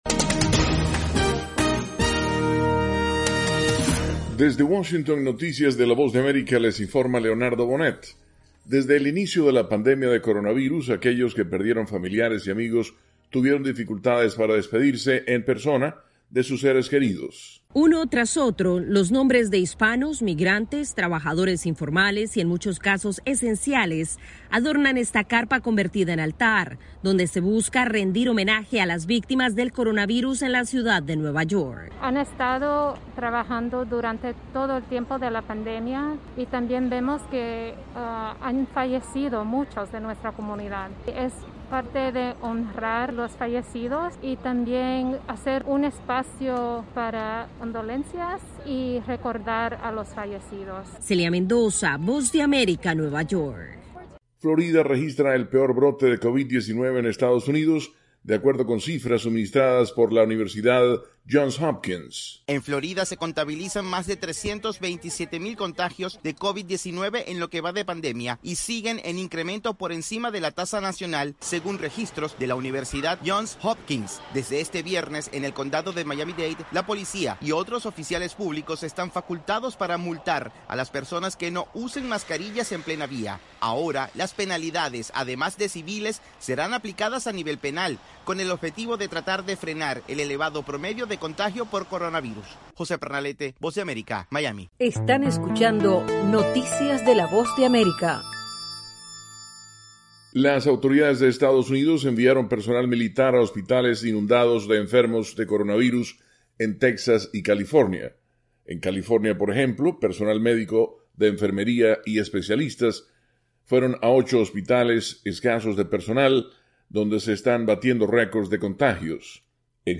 Segmento informativo de 3 minutos con noticias de Estados Unidos y el resto del mundo.